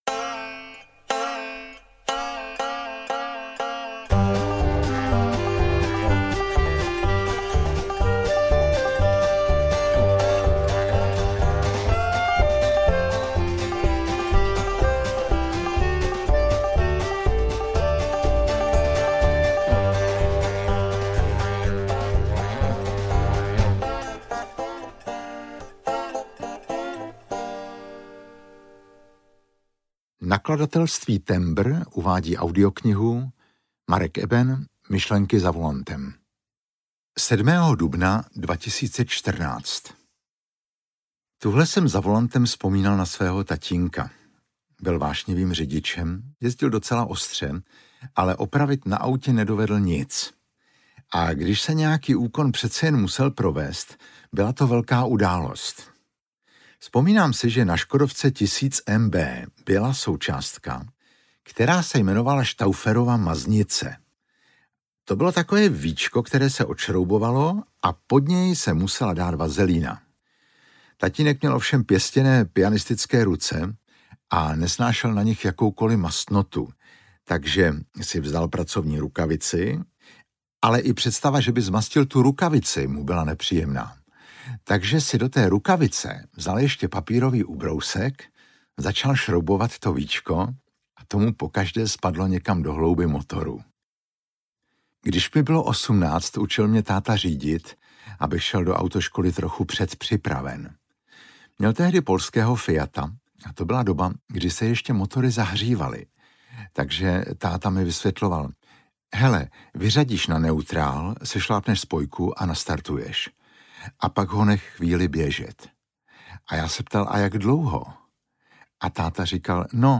Myšlenky za volantem audiokniha
Ukázka z knihy
• InterpretMarek Eben